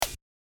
スネア
-12再生時
snare-12.mp3